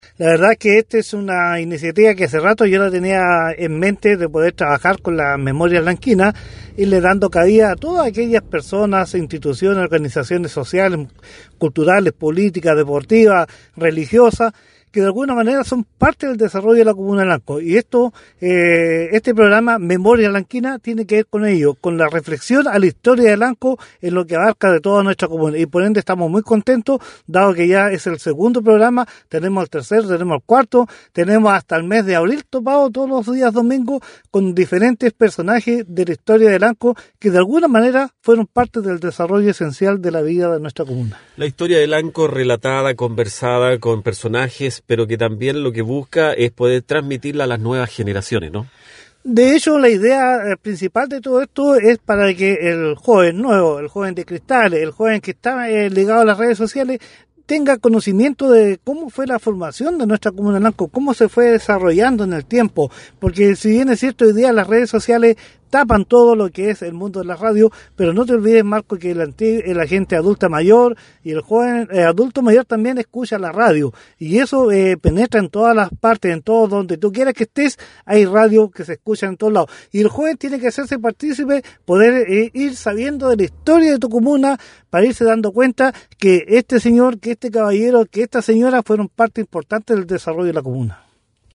Programa radial «Memorias Lanquina: Una Mirada al Pasado y Presente» rescata la historia de Lanco